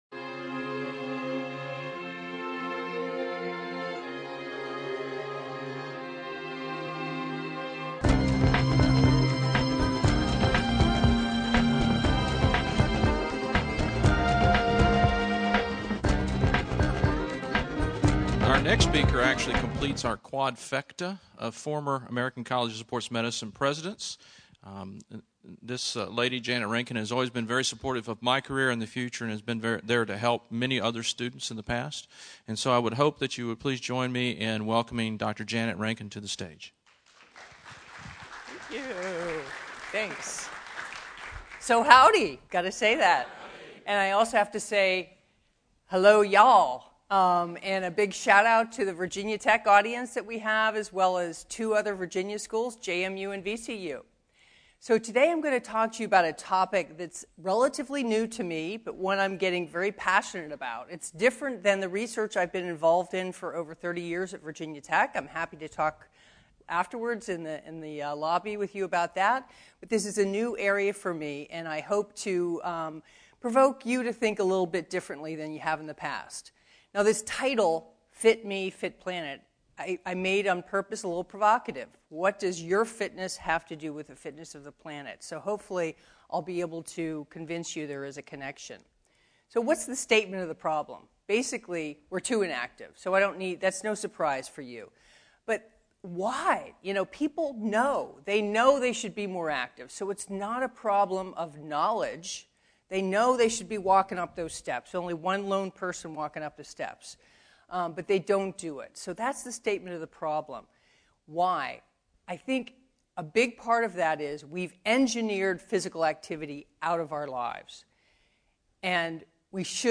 Today is the fifth video and audio-only rebroadcast from the 2013 Huffines Discussion.